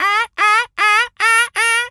Animal_Impersonations
seagul_squawk_seq_03.wav